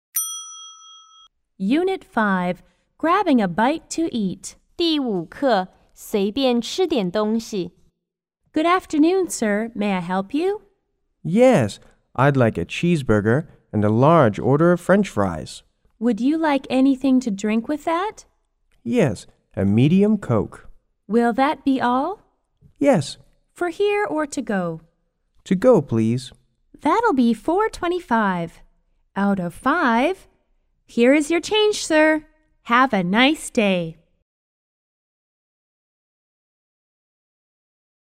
A= Attendant C= Customer